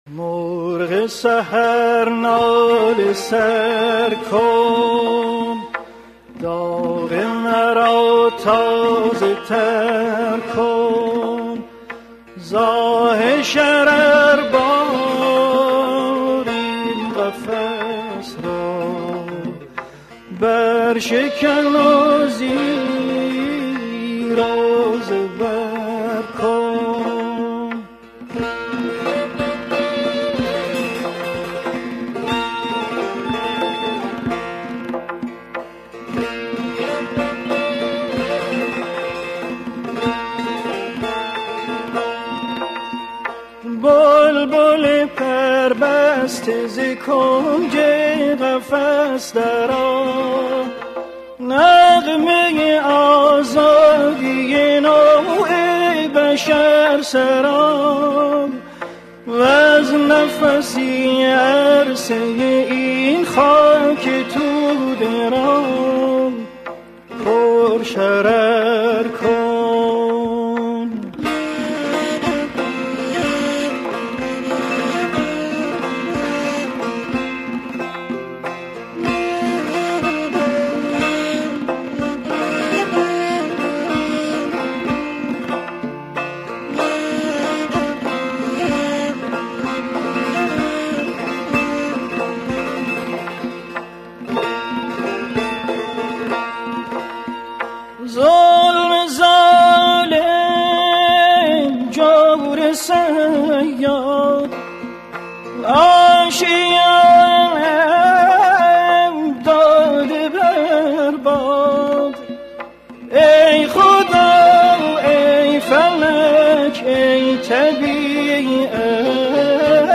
تصنیف
آواز درد و امید